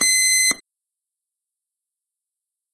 timerClick.wav